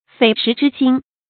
匪石之心 fěi shí zhī xīn
匪石之心发音